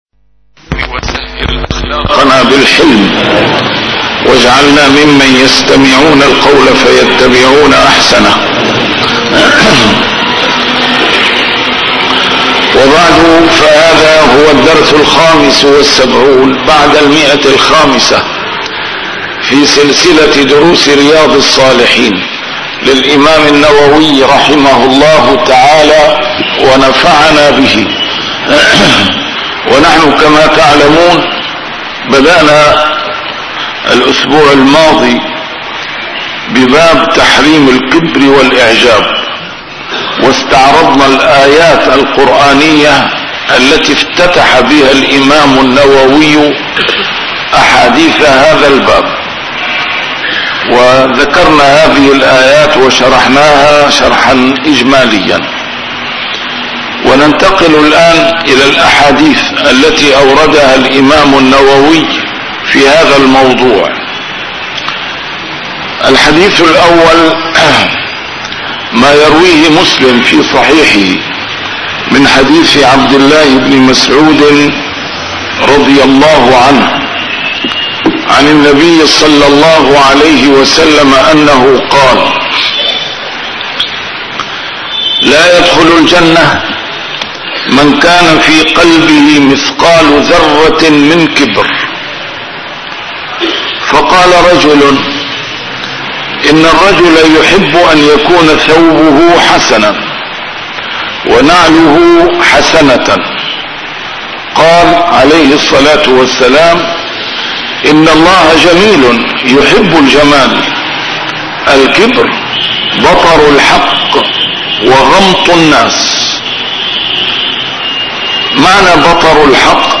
A MARTYR SCHOLAR: IMAM MUHAMMAD SAEED RAMADAN AL-BOUTI - الدروس العلمية - شرح كتاب رياض الصالحين - 575- شرح رياض الصالحين: تحريم الكبر والإعجاب